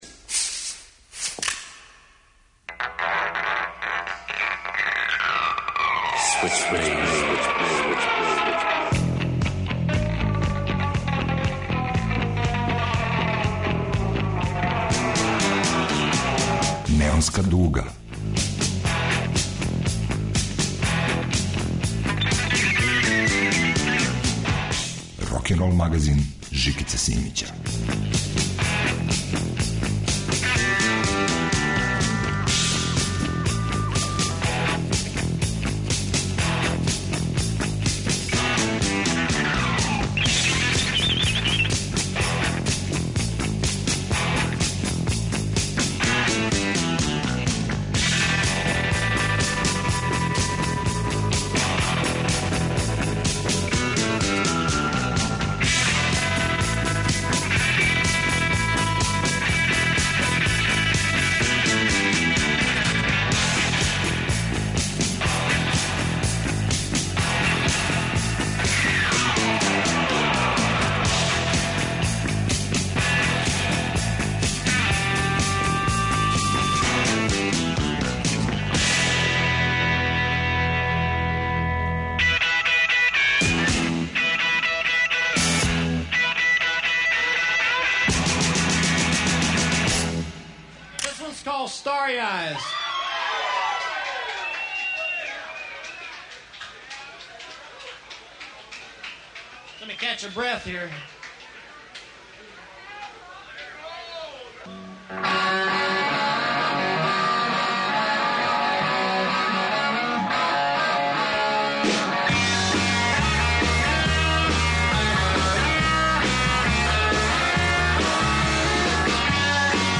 Психоделични аргонаут свира тешки рок.